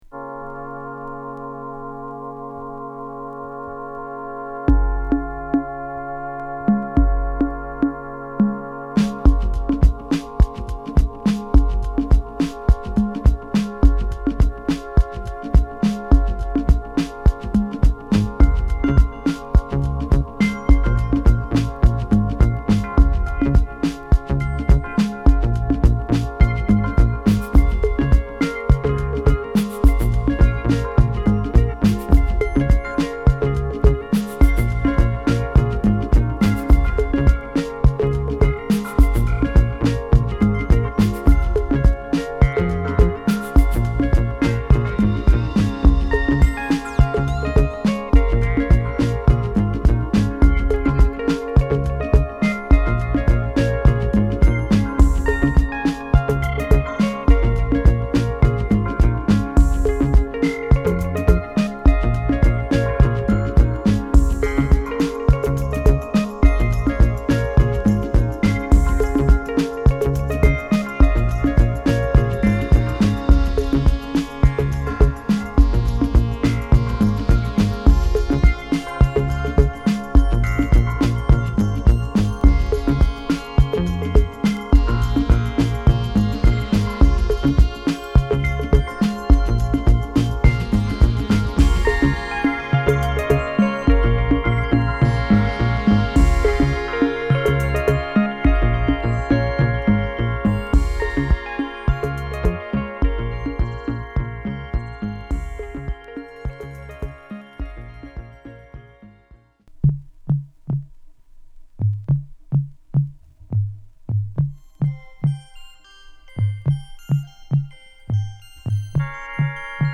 SideAはオリジナルver.で、バレアリックなミッドグルーヴが心地よい1曲を！